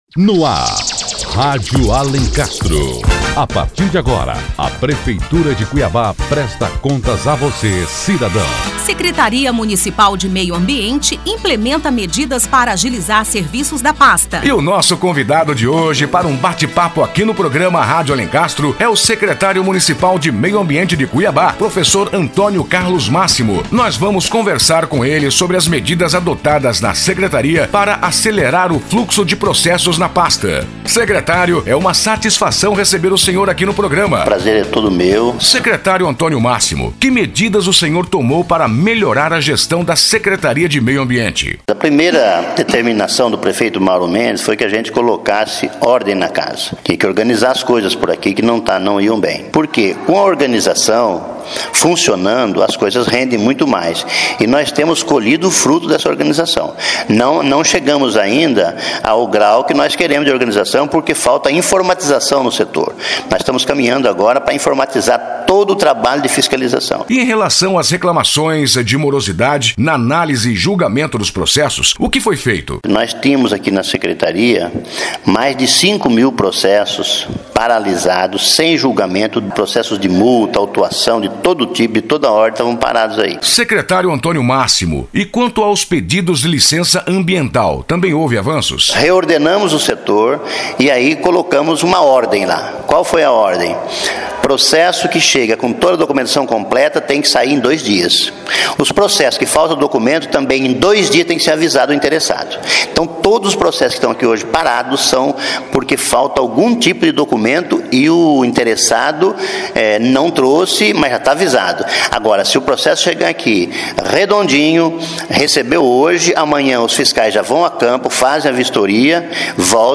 Entrevista com o Secretário Municipal de Meio Ambiente | Notícias - Prefeitura de Cuiabá